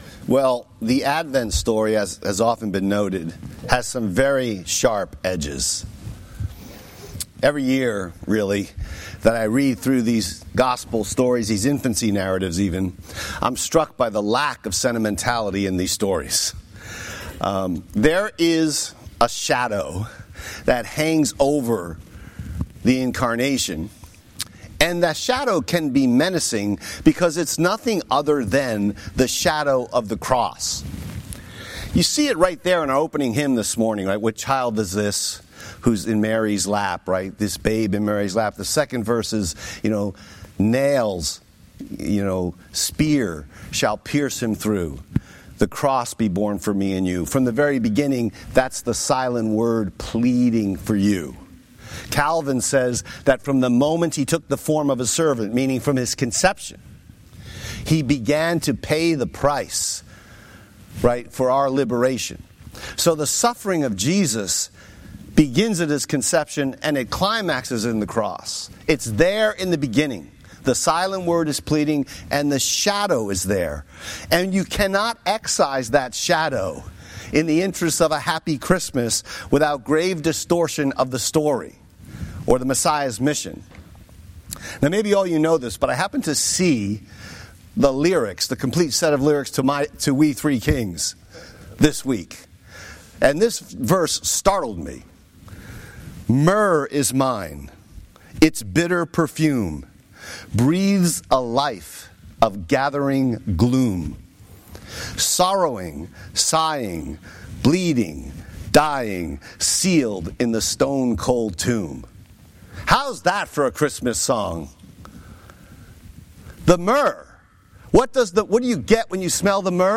Sermon text: Matthew 2:13-23